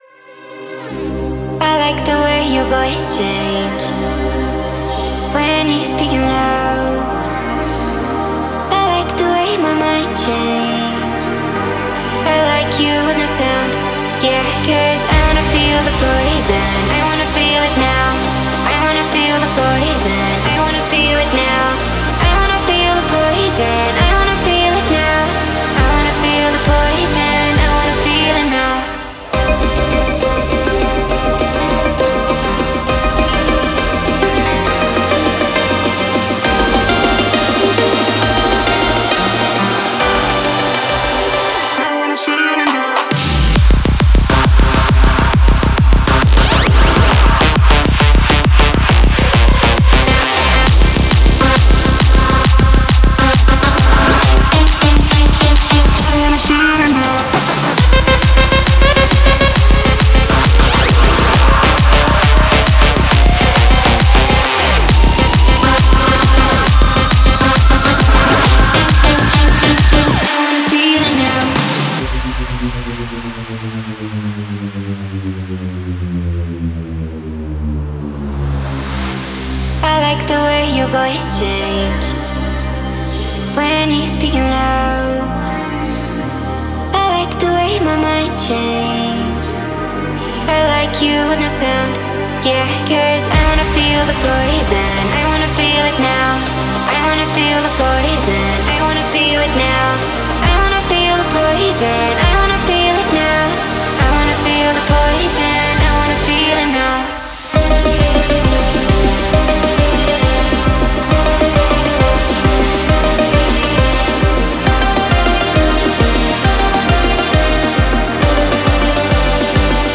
Клубная